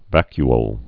(văky-ōl)